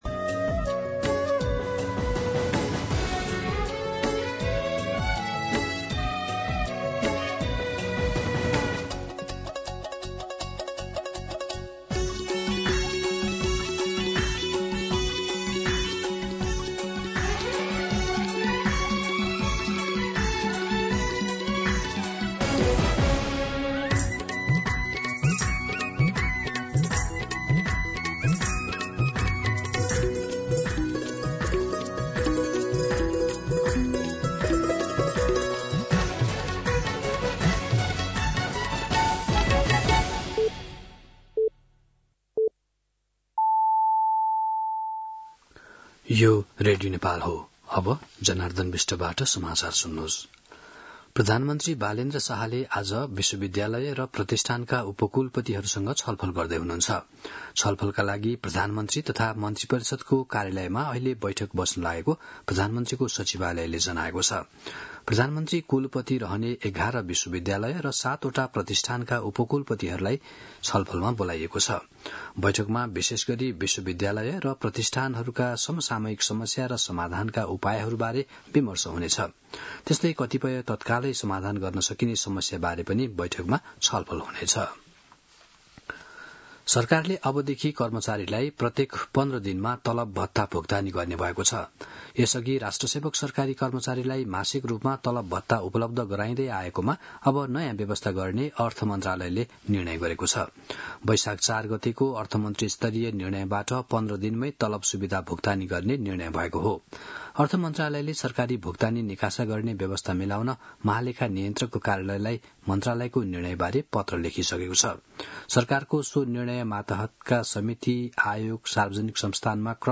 दिउँसो १ बजेको नेपाली समाचार : ७ वैशाख , २०८३